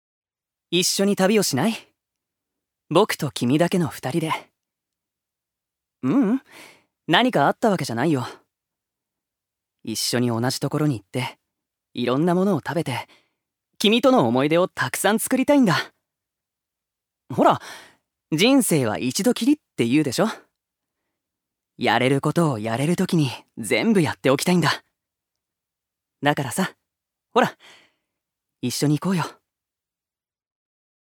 所属：男性タレント
セリフ４